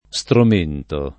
strumento [Strum%nto] s. m. — ant. o poet. stromento [